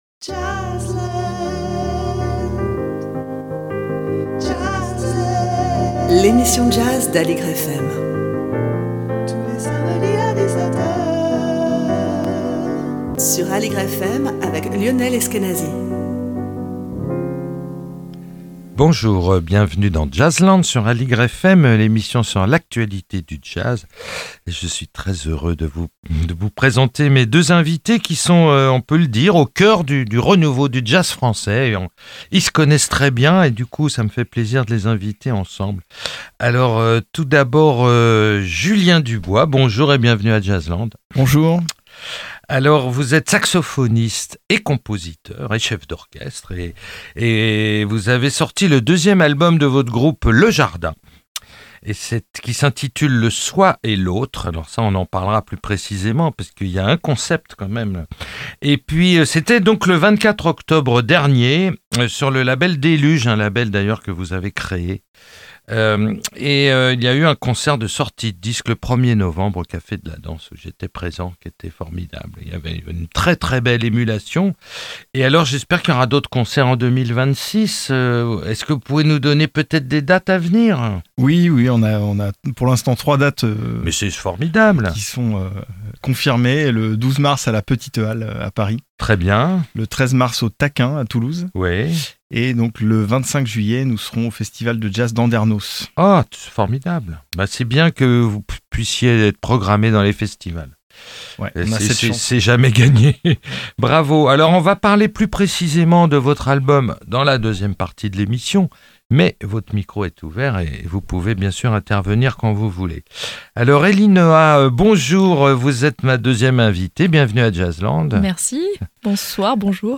Emission
avec deux invités